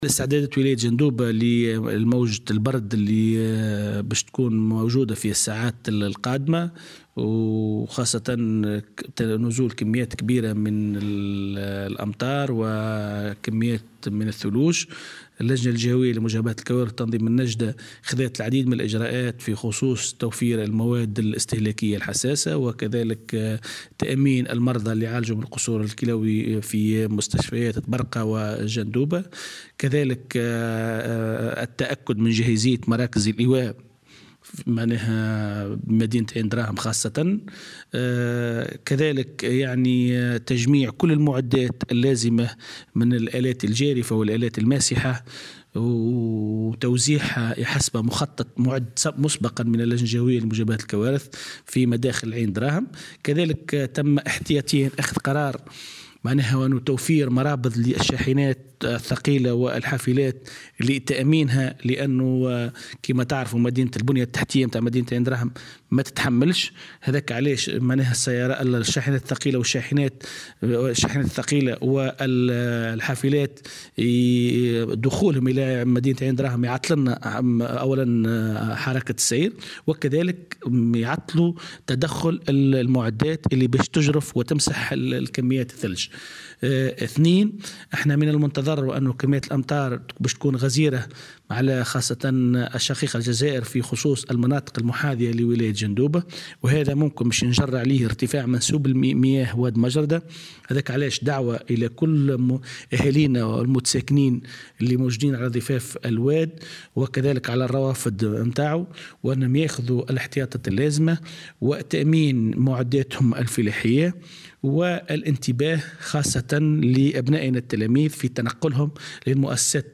أعلن والي جندوبة محمد الصدقي بوعون في تصريح اليوم الأربعاء لمراسل "الجوهرة اف أم" استعدادات اللجنة الجهوية لمجابهة الكوارث، تحسبا لموجة برد شديدة منتظرة خلال الساعات القادمة مصحوبة بثلوج وهطول أمطار غزيرة.